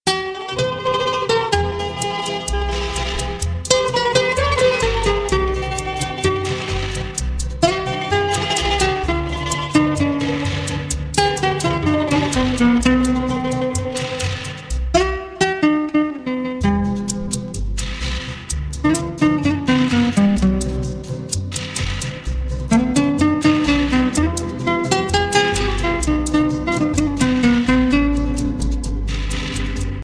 • Качество: 128, Stereo
гитара
спокойные
без слов
красивая мелодия
инструментальные
испанские
Фламенко
Приятная мелодия в испанском духе